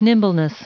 Prononciation du mot nimbleness en anglais (fichier audio)
Prononciation du mot : nimbleness